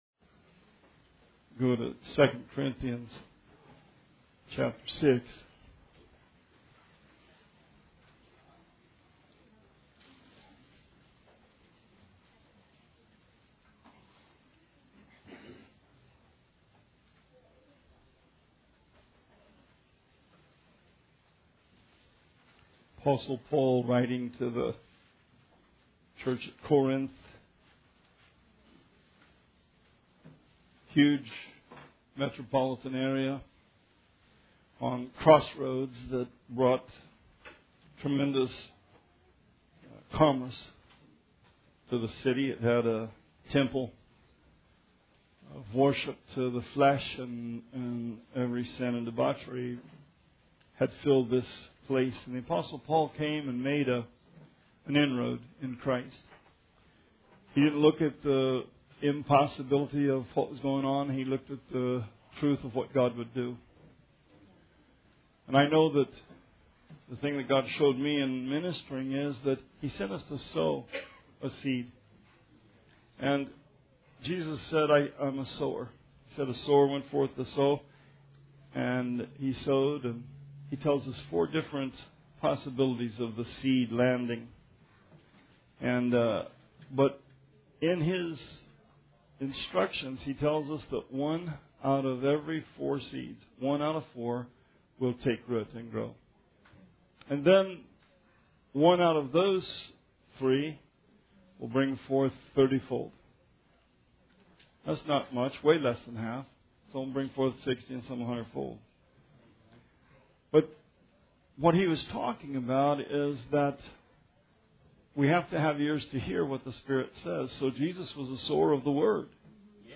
Sermon 7/29/18